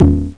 1 channel
catchball.mp3